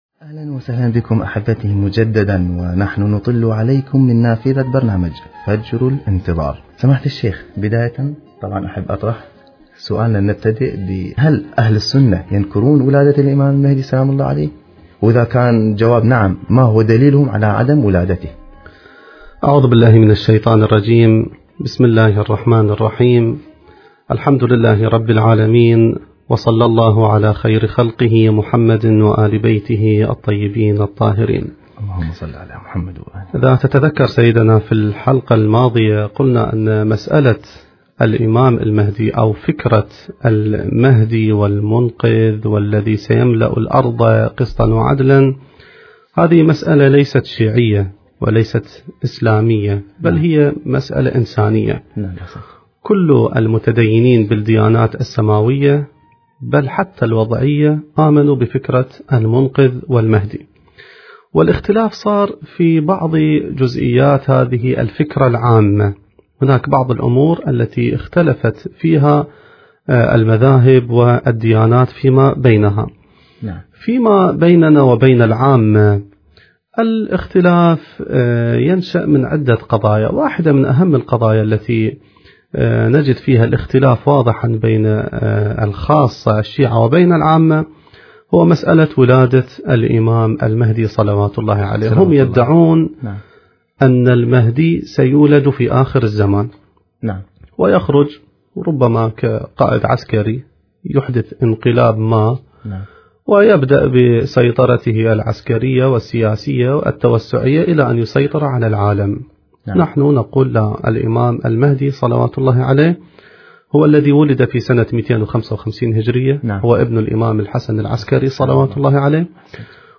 المكان: اذاعة الفرات